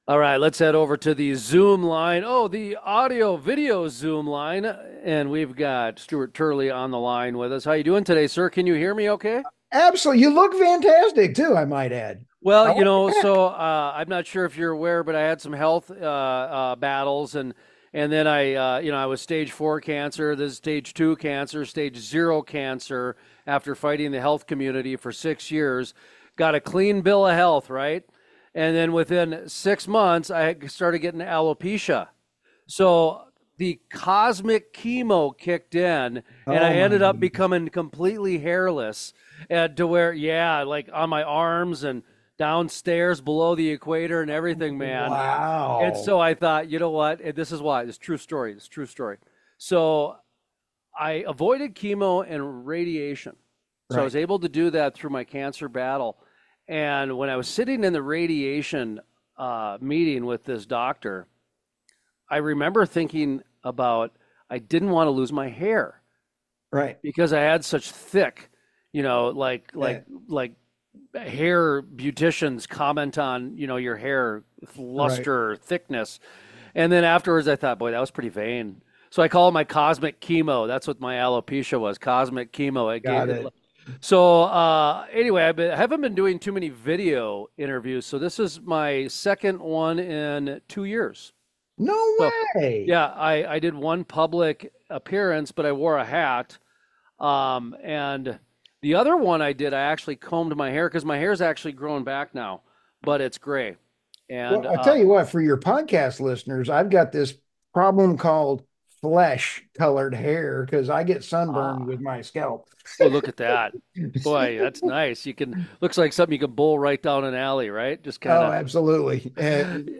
After some friendly banter, the interview dives into the evolution of politics being injected into energy.
Full Length Interviews